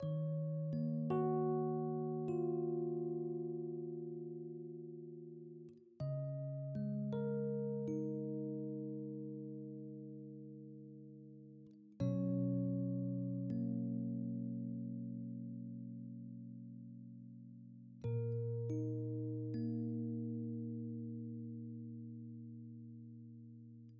Just a Rhodes loop
描述：Some jazzy Rhodes chords.
标签： rhodes
声道立体声